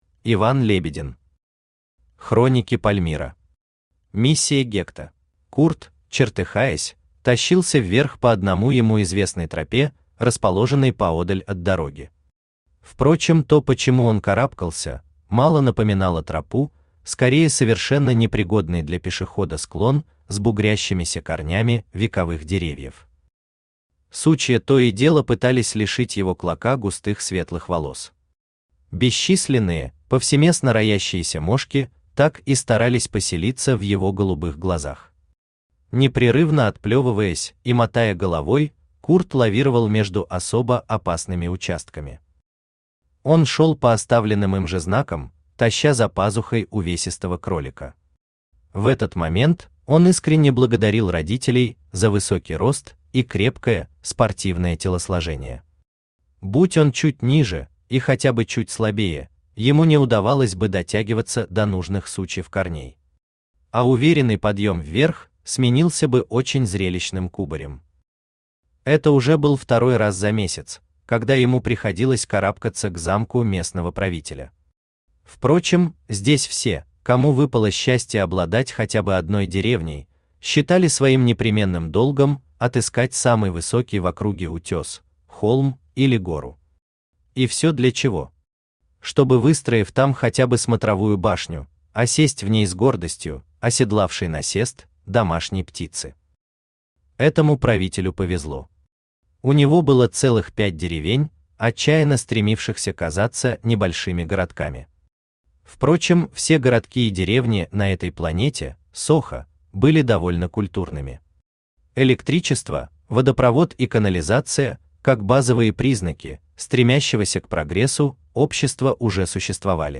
Аудиокнига Хроники Пальмирро. Миссия «Гекта» | Библиотека аудиокниг
Миссия «Гекта» Автор Иван Лебедин Читает аудиокнигу Авточтец ЛитРес.